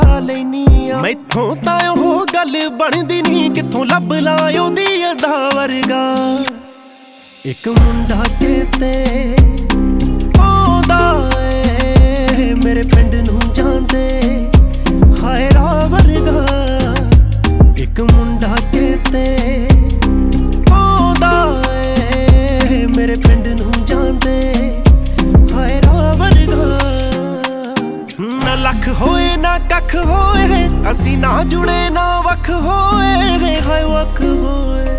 Жанр: Инди / Местная инди-музыка